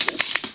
crossbow_setup.WAV